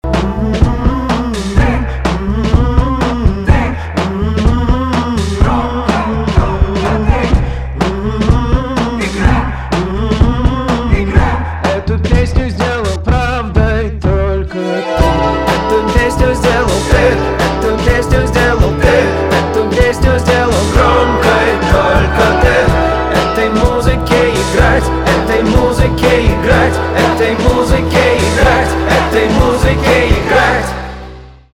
инди
барабаны , гитара
чувственные